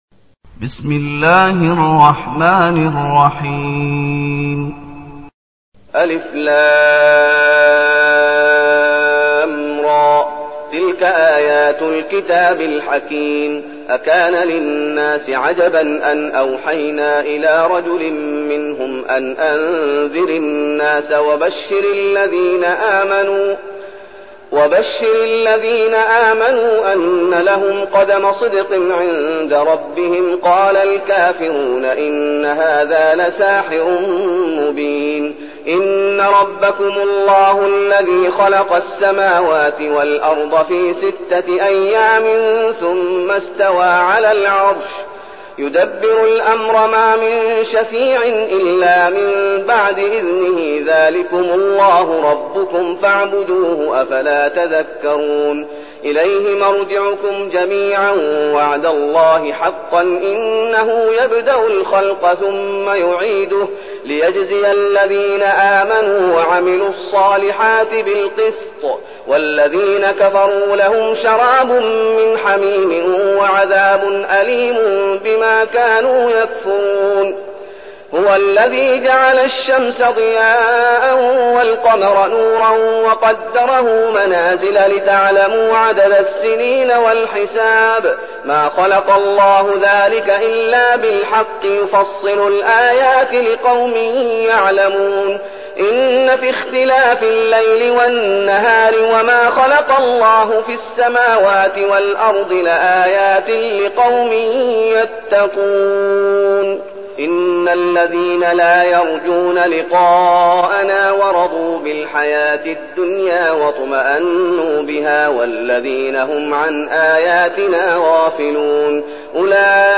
المكان: المسجد النبوي الشيخ: فضيلة الشيخ محمد أيوب فضيلة الشيخ محمد أيوب يونس